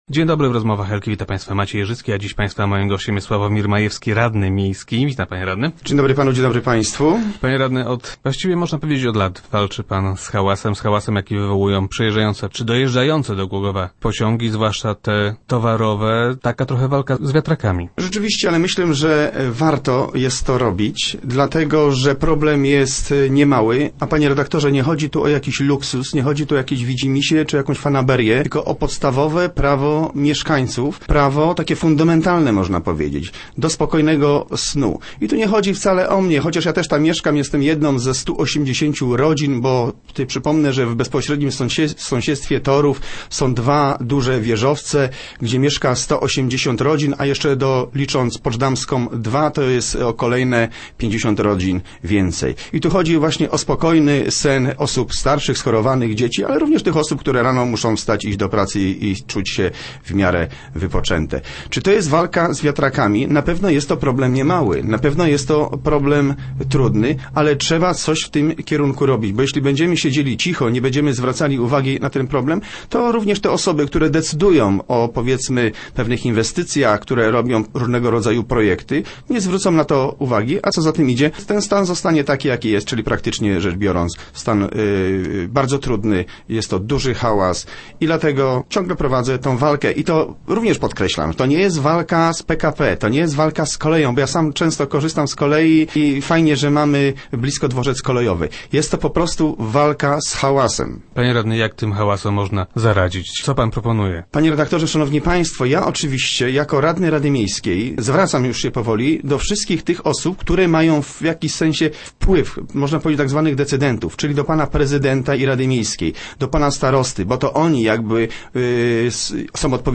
- Sytuację poprawiłoby też ustawienie ekranów – zapewnia samorządowiec, który był gościem Rozmów Elki.